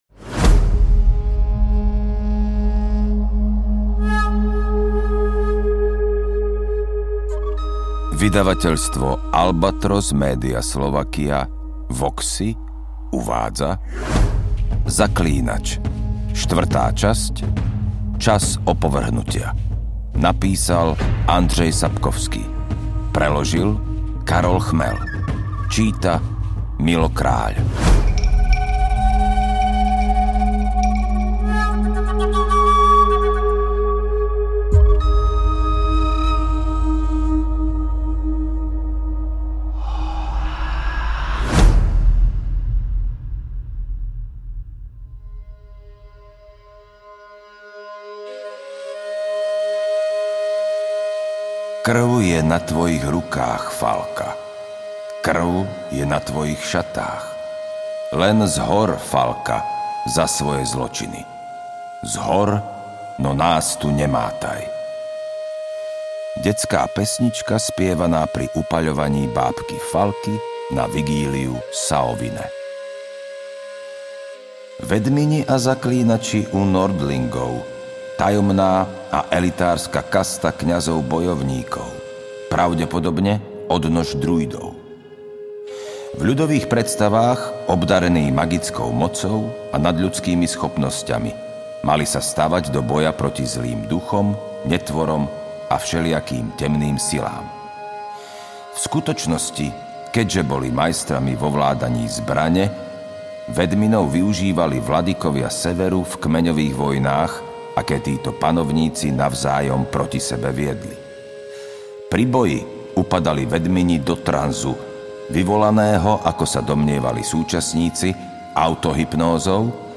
AudioKniha ke stažení, 48 x mp3, délka 14 hod. 44 min., velikost 840,2 MB, slovensky